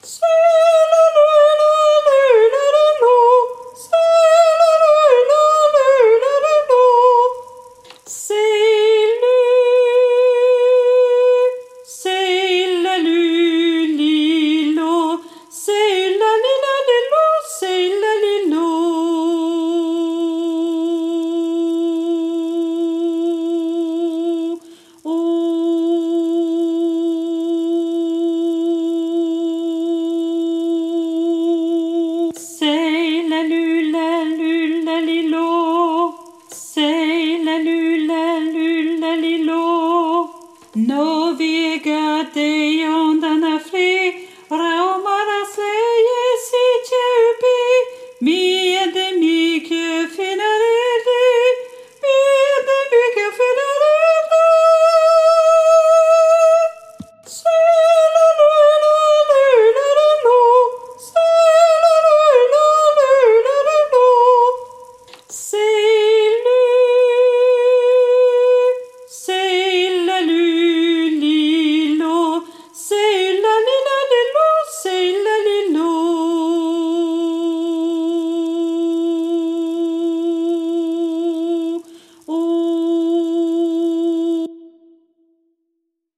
- Chant pour 4 voix mixtes SATB
MP3 versions chantées
Soprano